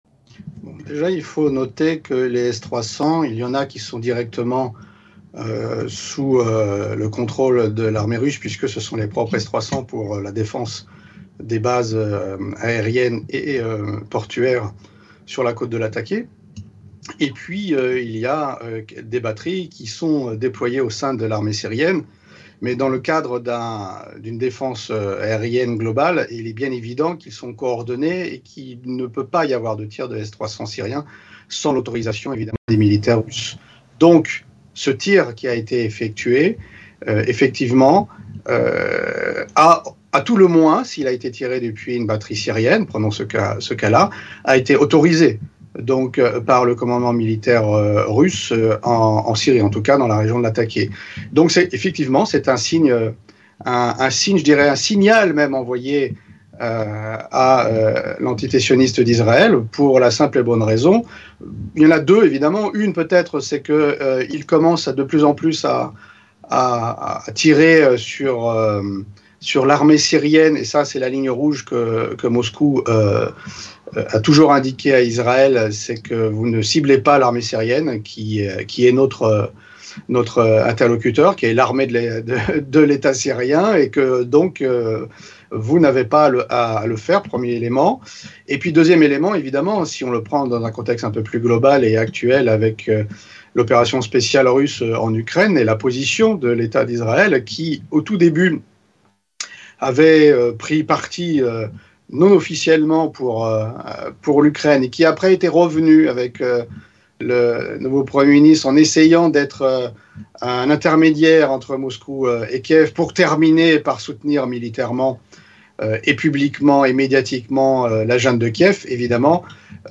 Mots clés Syrie Israël Russie interview Eléments connexes Un journal israélien év